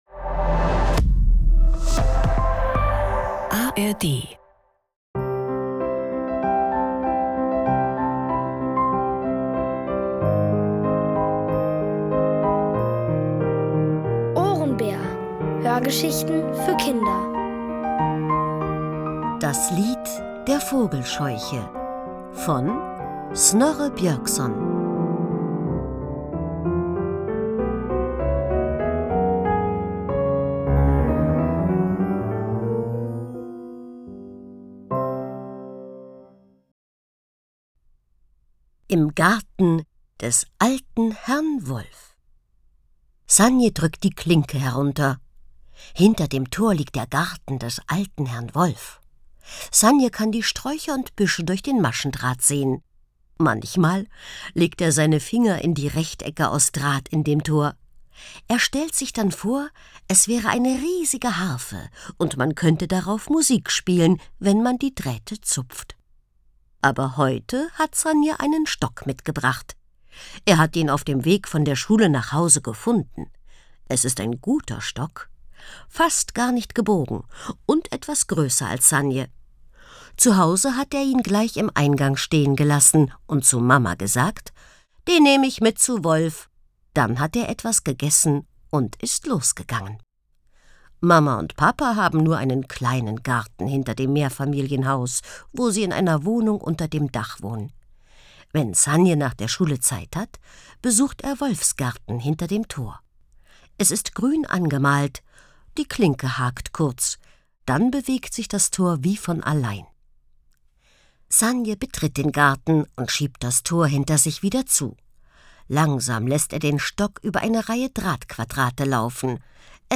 Alle 5 Folgen der OHRENBÄR-Hörgeschichte: Das Lied der Vogelscheuche von Snorre Björkson.